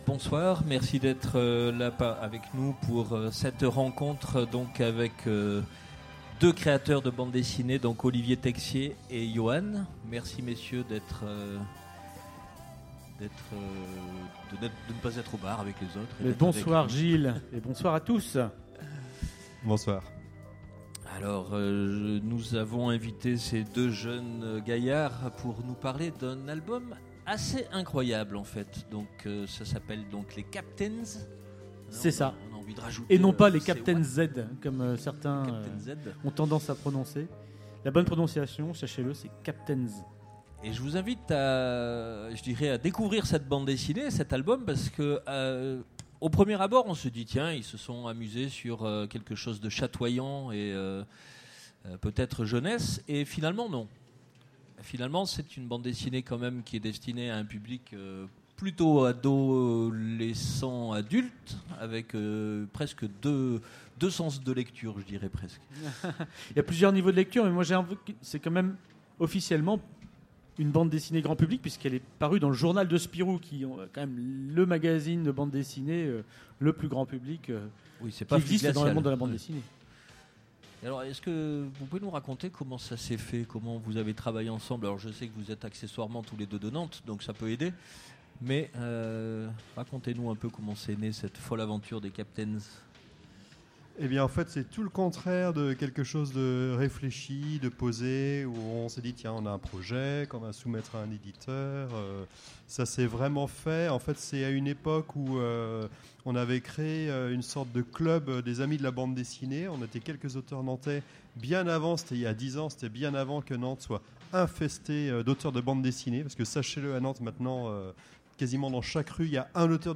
Utopiales 2017 : Conférence Les Captainz